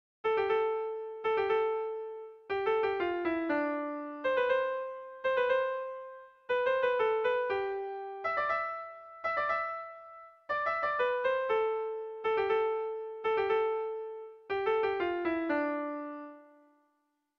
Sentimenduzkoa
Zortziko berdina, 4 puntuz eta 6 silabaz (hg) / Lau puntuko berdina, 12 silabaz (ip)
ABDA